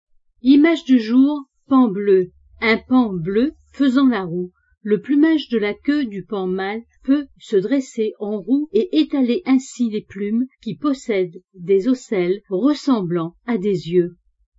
L'IMAGE DU JOUR: Paon bleu
Paon bleu.mp3 (114.08 Ko) Le plumage de la queue du paon mâle peut se dresser en roue et étaler ainsi les plumes qui possèdent des ocelles ressemblant à des yeux.